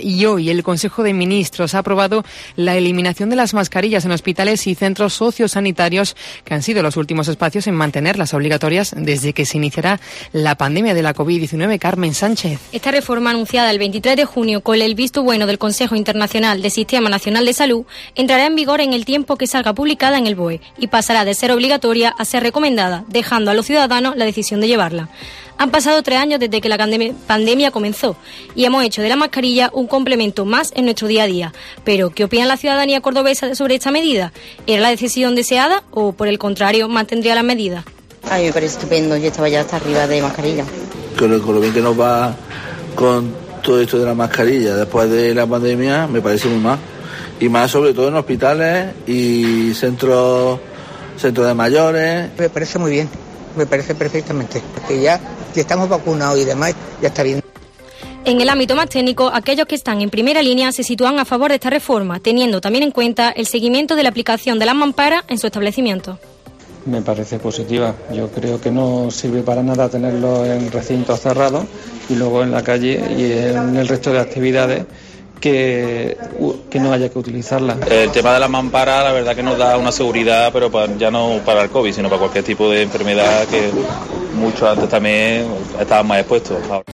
Hoy en COPE hemos recorrido calles y farmacias, donde nos hemos encontrado opiniones diversas, aunque la gran mayoría optan por la retirada.